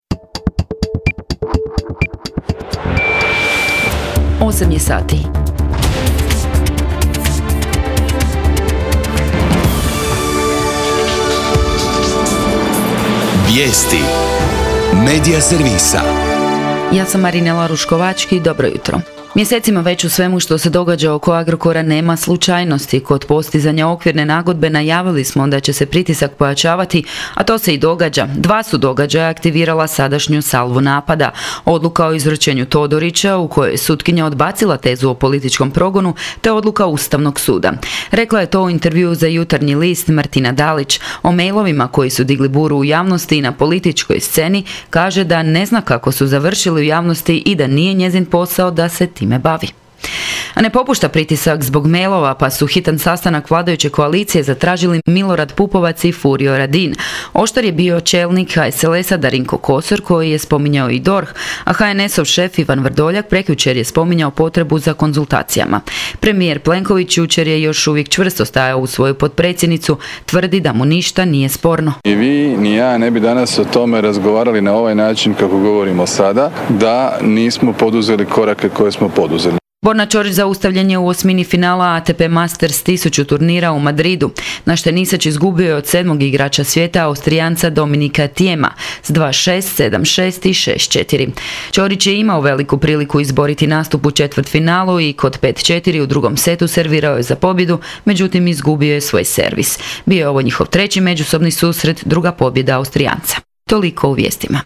VIJESTI U 8